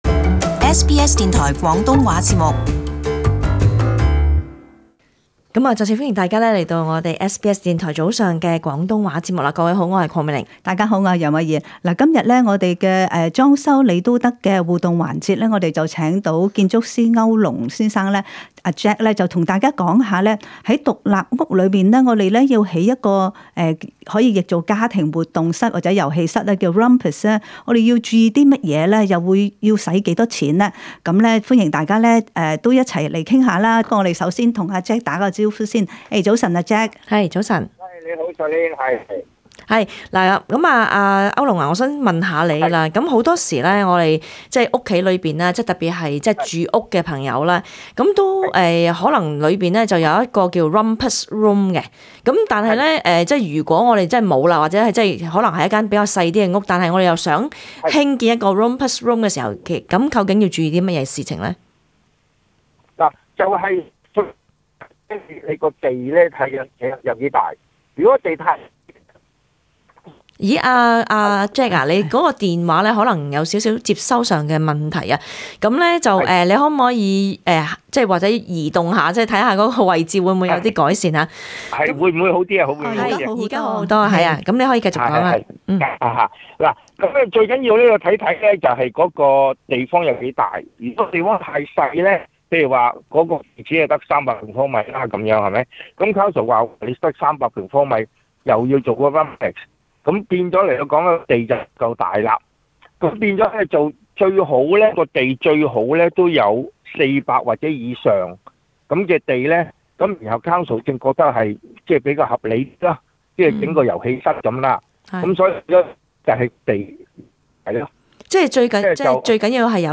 互動環節